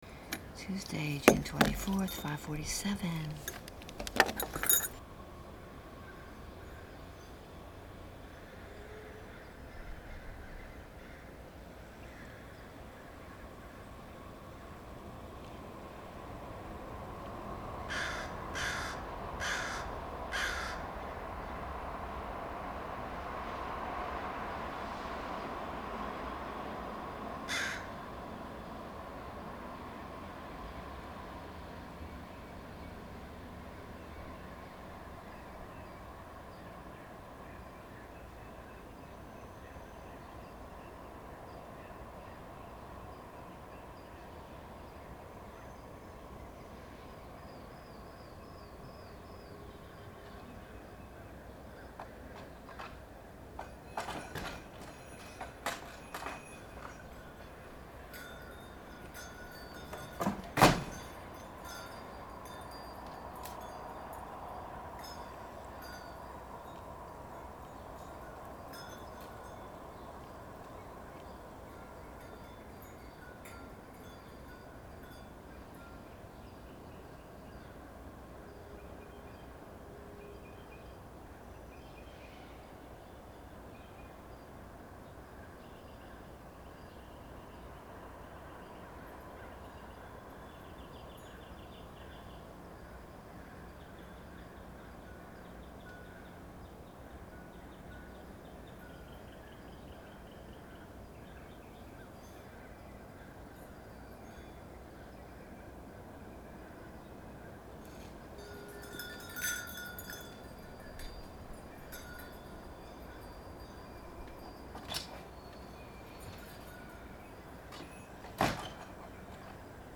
Find the bells if and when you can, locate them in the space by listening to how they sound in relation to the other sounds.
6/24 5:49 AM, San Francisco.
The bells don’t have to figure so prominently in the documentation anymore.
I’ll set the recorder up somewhere and leave it in place while I move around the area with the bells.
I’d like to know which sounds carry the farthest and how the bells mix into the morning soundscape when they don’t figure so prominently.